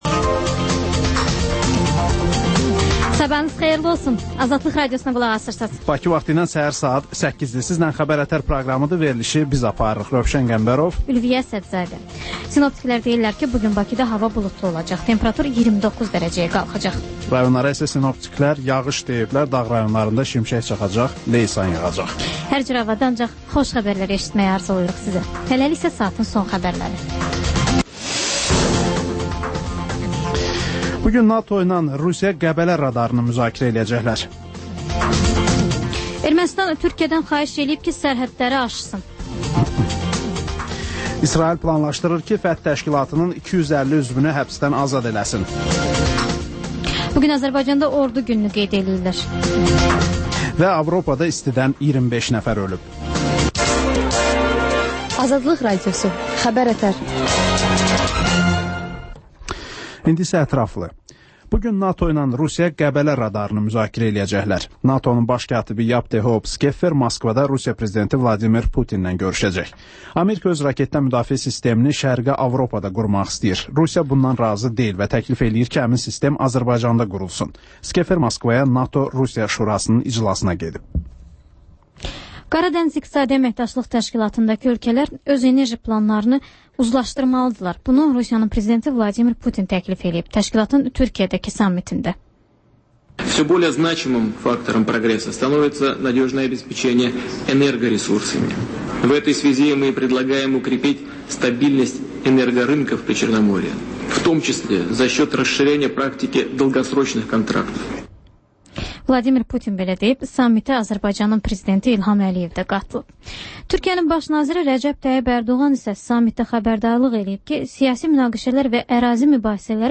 Xəbər-ətər: xəbərlər, müsahibələr, sonda XÜSUSİ REPORTAJ rubrikası: Ölkənin ictimai-siyasi həyatına dair müxbir araşdırmaları